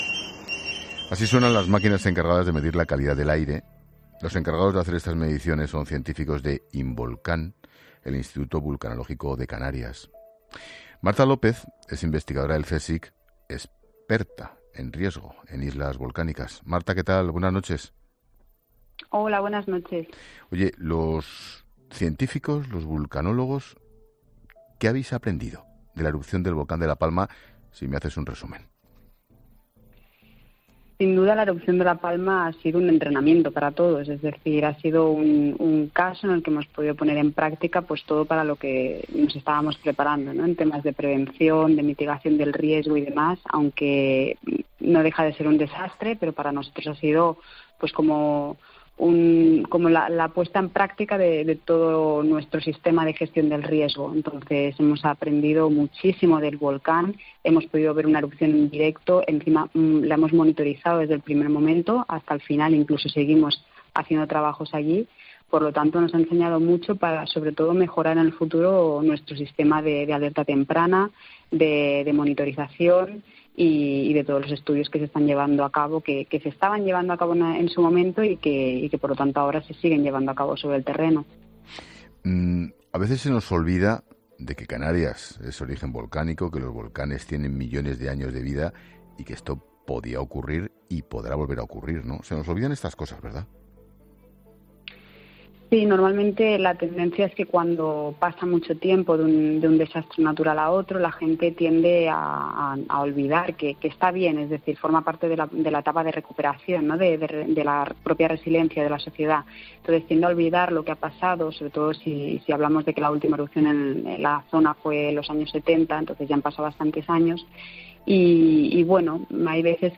Con Ángel Expósito